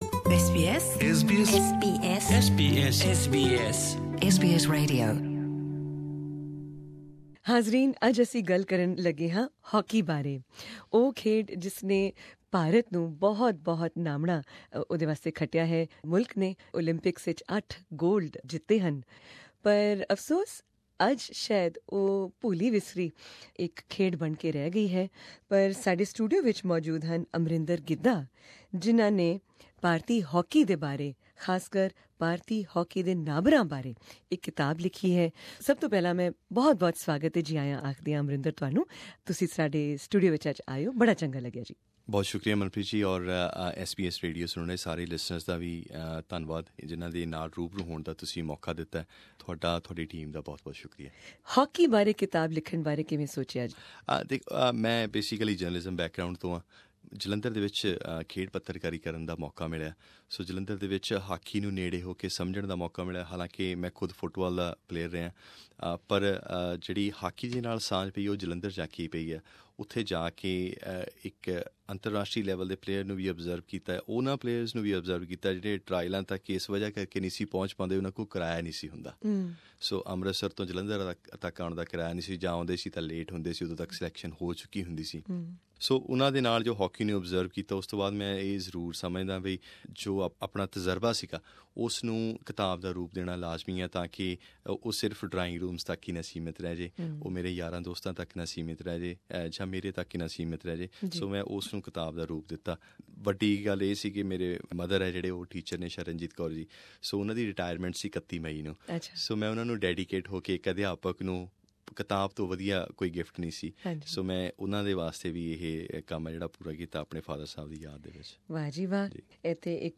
More interviews and articles from SBS Punjabi